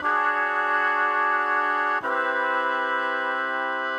Index of /musicradar/gangster-sting-samples/120bpm Loops
GS_MuteHorn_120-D.wav